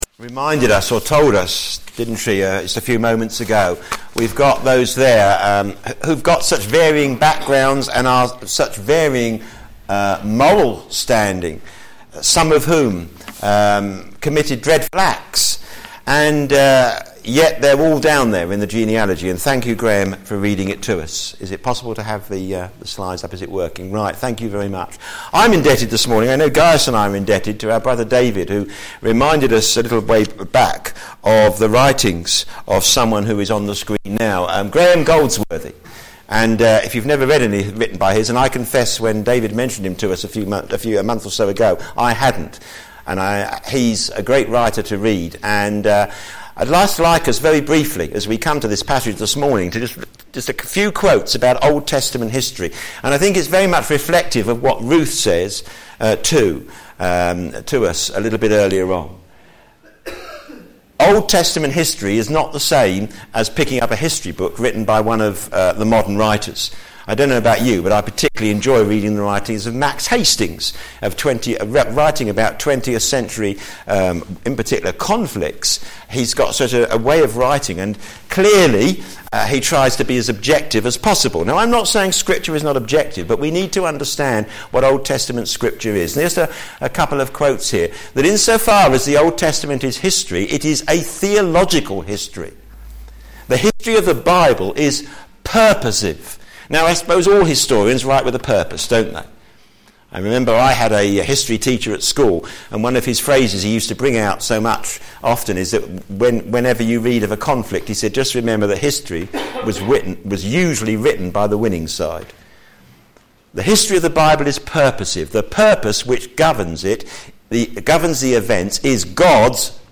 Media Library Media for a.m. Service on Sun 14th Dec 2014 10:30 Speaker
A Unique Baby Theme: Where did Jesus come from? Heaven! Sermon